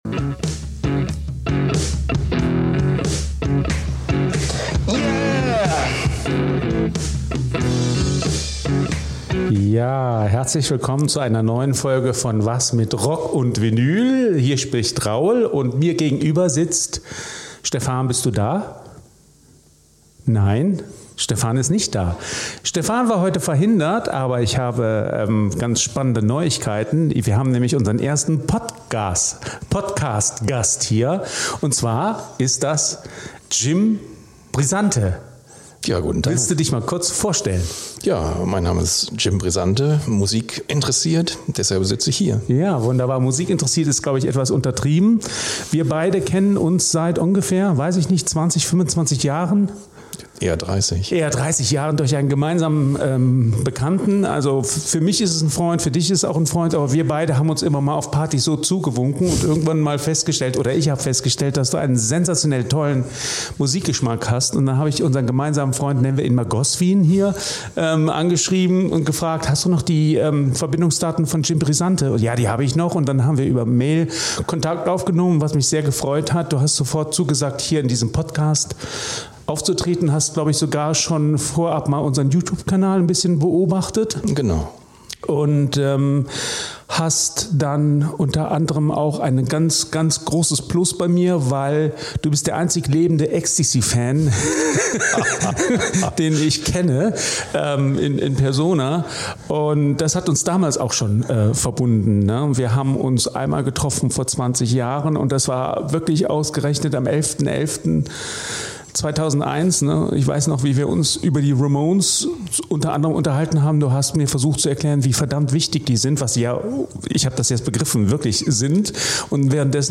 April 2022 Nächste Episode download Beschreibung Teilen Abonnieren Wir feiern Premiere und präsentieren heute stolz unseren ersten Gast.